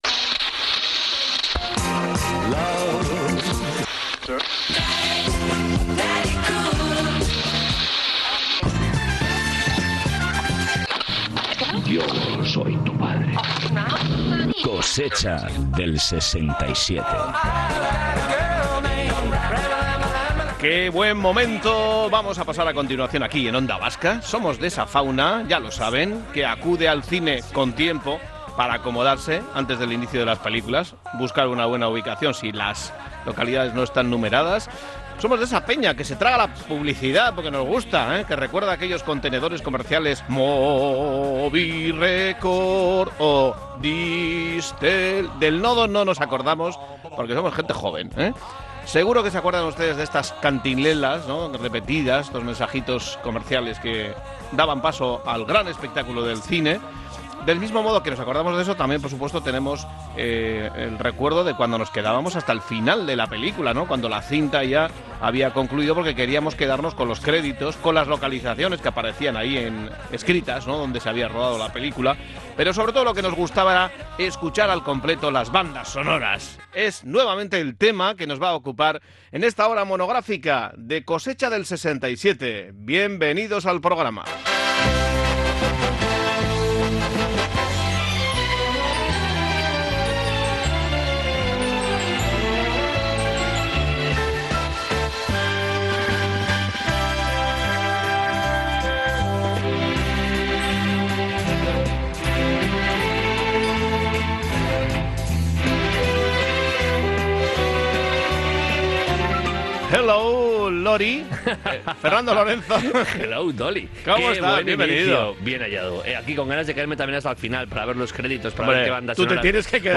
Espacio conectado a la nostalgia a través del humor y la música.